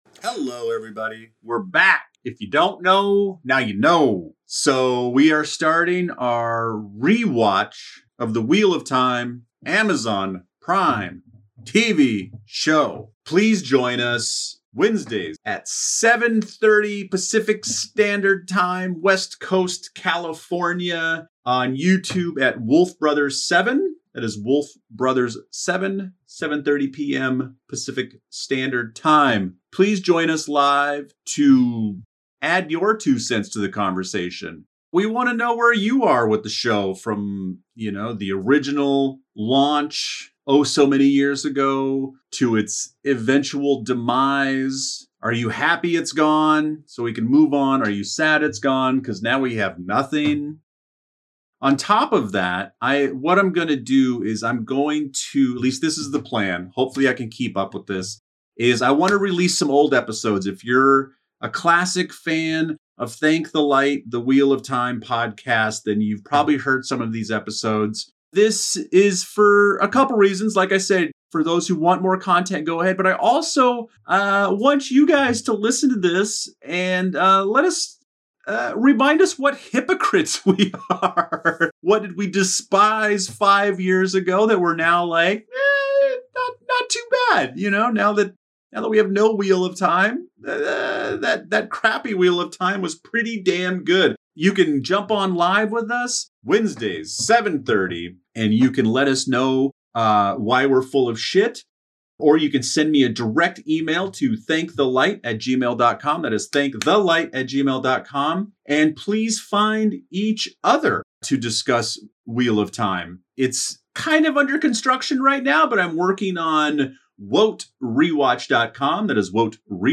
Tune in for a spirited conversation that promises to entertain and provoke thought about this epic saga!